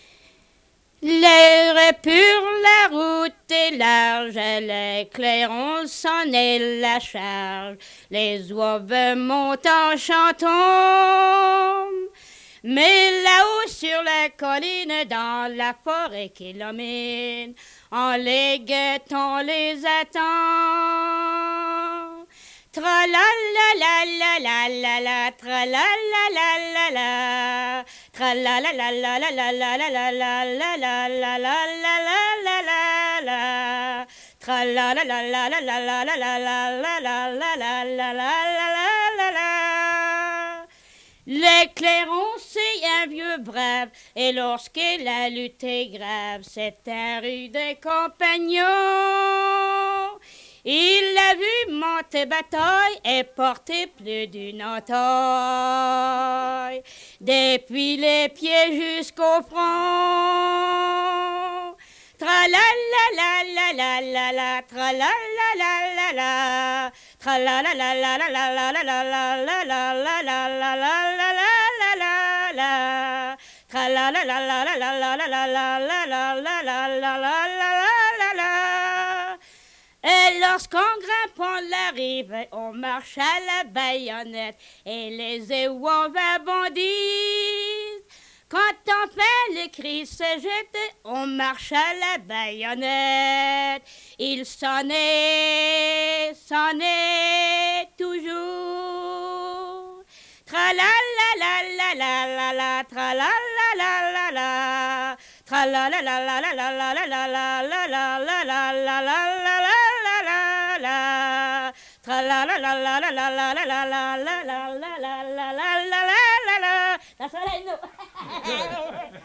Chanson Item Type Metadata
Cap St-Georges